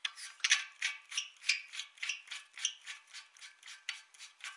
散热器打开阀门
描述：打开散热器取暖